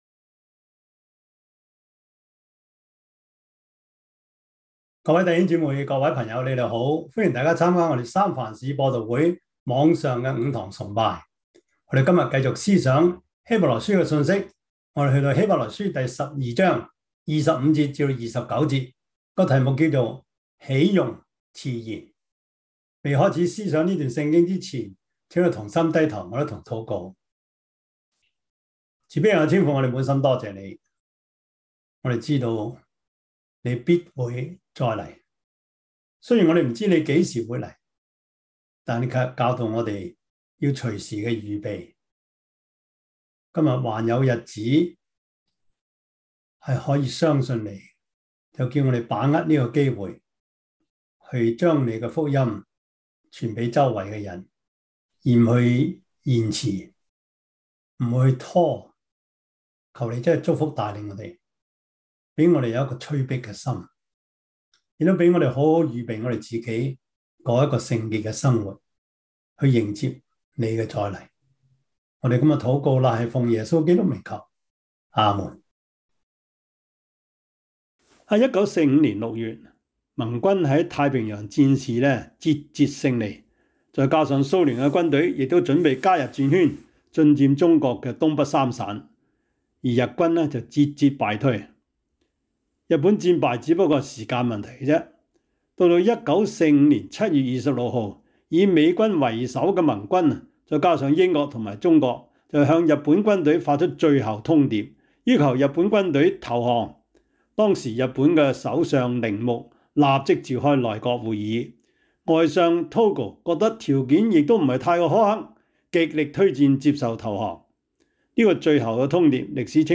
希伯來書12:25-29 Service Type: 主日崇拜 希 伯 來 書 12:25-29 Chinese Union Version
Topics: 主日證道 « 把別人介紹給主 – 第二課 上樑不正 »